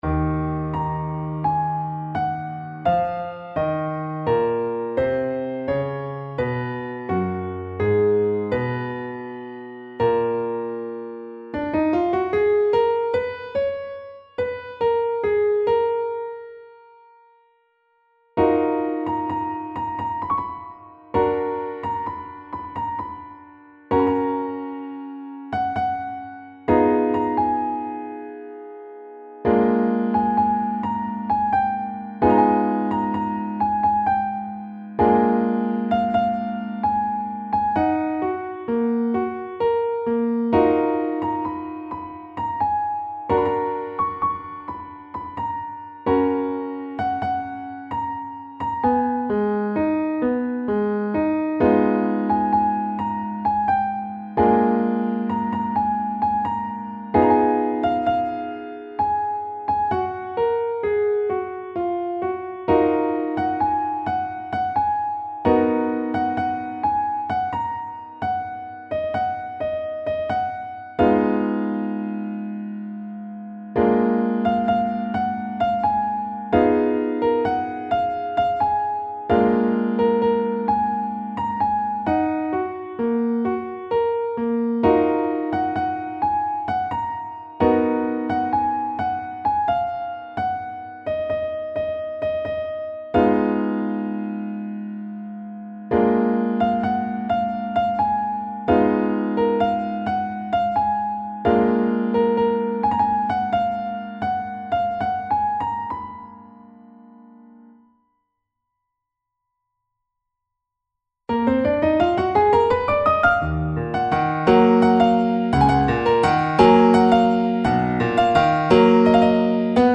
نت پیانو